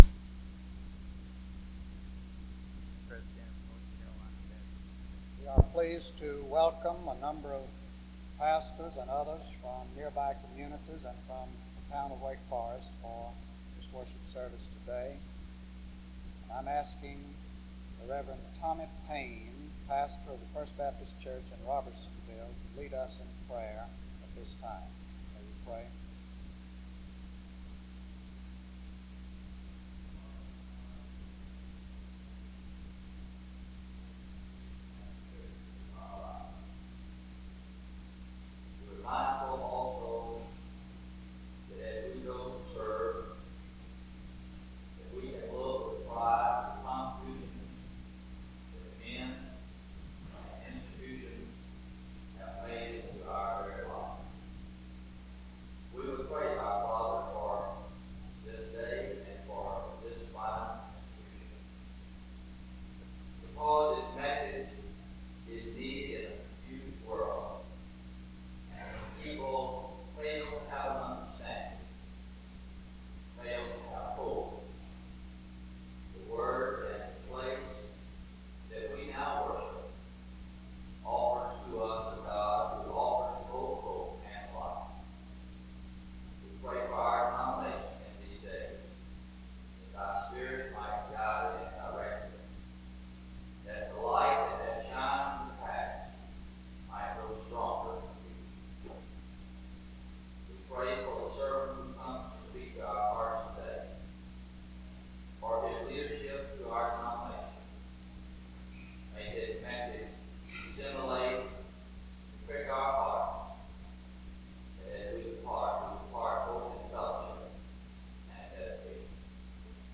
SEBTS Chapel
The service then concludes with a song (26:10-end).
SEBTS Chapel and Special Event Recordings SEBTS Chapel and Special Event Recordings